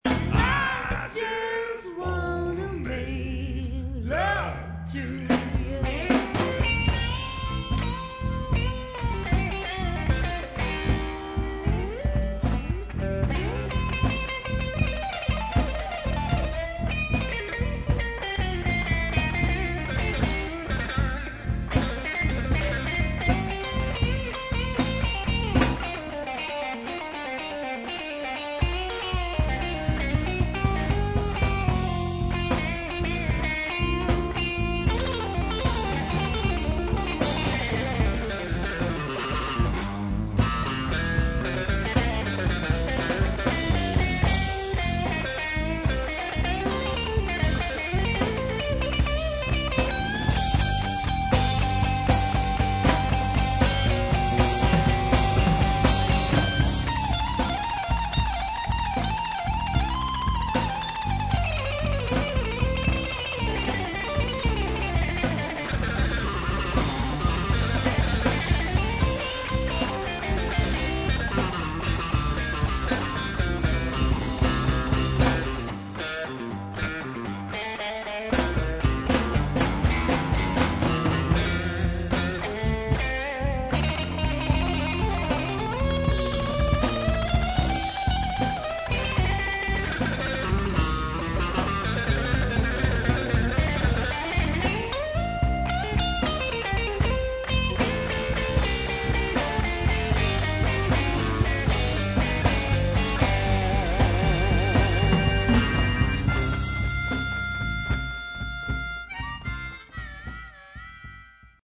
Mixed bag of solo excerpts    [ top ]
blues guitar